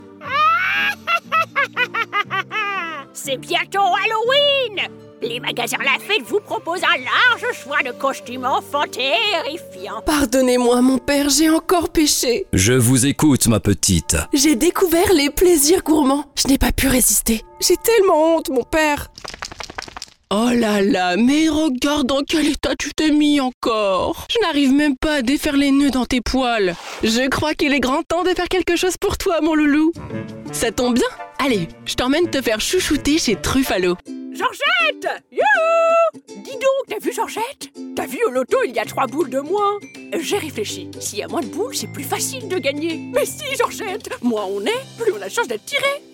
Comedy commercial demo
I have a medium deep voice with a large voice palette and many crazy characters at the service of your fairy tails, video games and commercials.